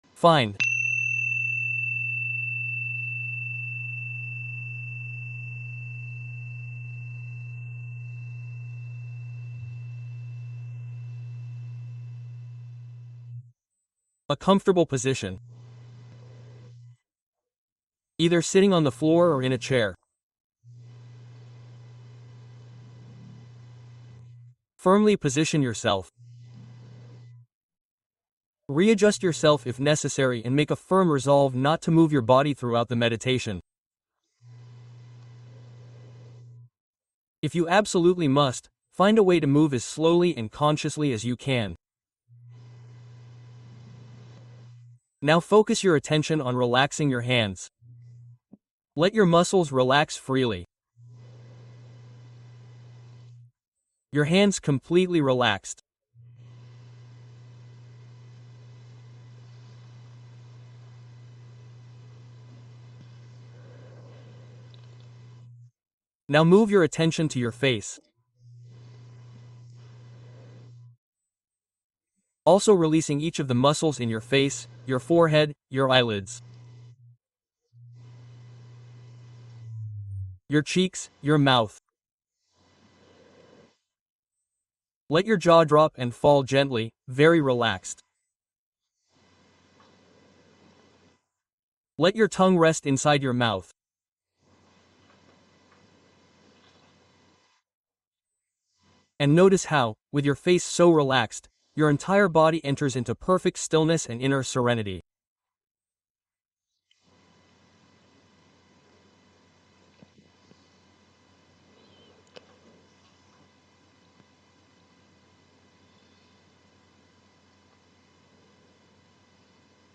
Escáner corporal corto: meditación guiada para liberar tensiones